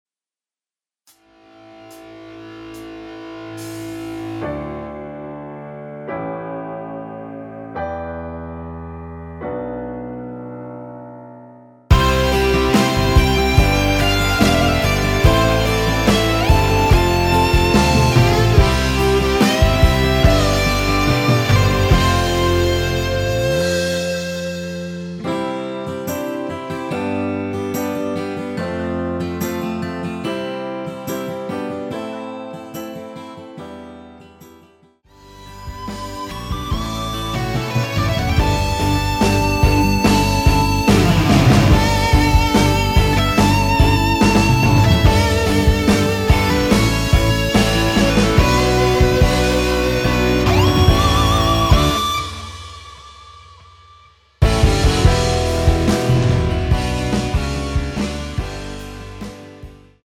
전주 없는 곡이라 전주1마디 만들어 놓았으며
앞부분30초, 뒷부분30초씩 편집해서 올려 드리고 있습니다.